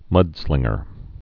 (mŭdslĭngər)